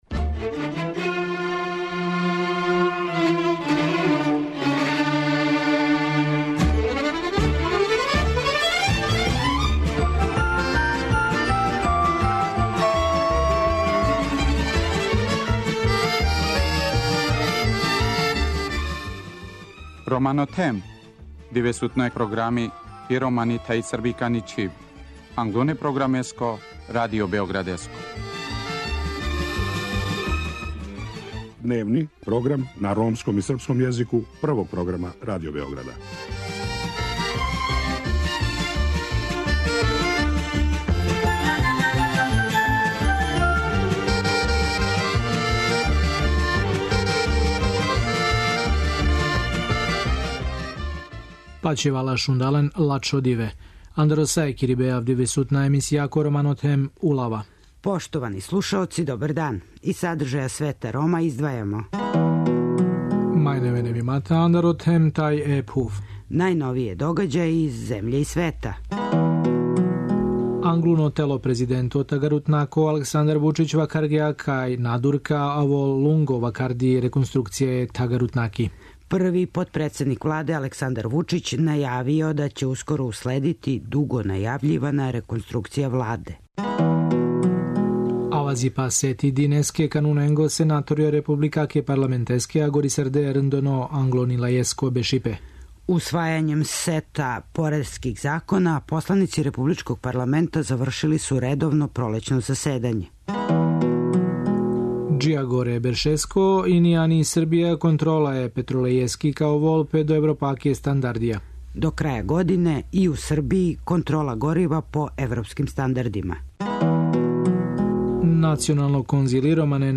Гост Света Рома је Сабахудин Делић, помоћник министра за мањинска права који говори о приоритетима црногорског председавања Декадом укључивања Рома.